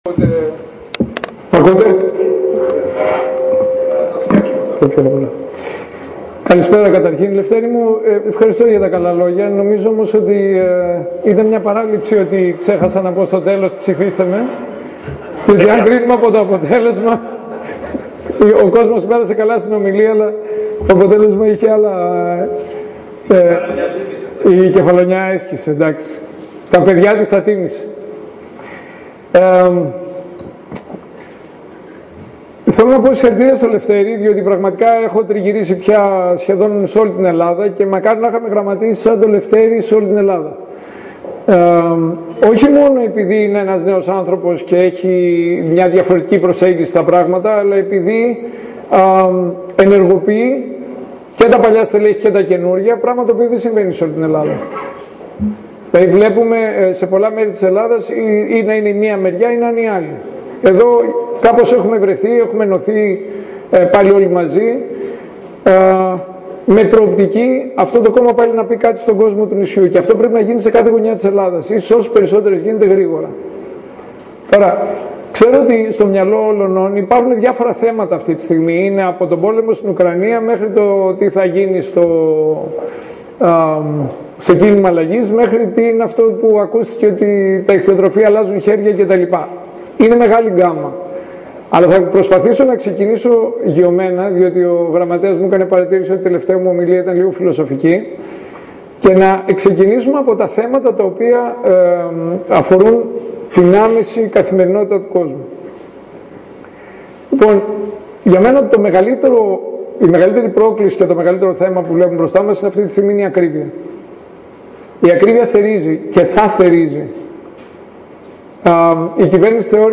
Αρκετός κόσμος παρακολούθησε την ομιλία, ιδιαίτερα να επισημάνουμε ότι μετά το τέλος της ομιλίας του Παύλου Γερουλάνου ακολούθησε συζήτηση.
Ακούστε ολόκληρη ηχογραφημένη την ομιλία του Παύλου Γερουλάνου.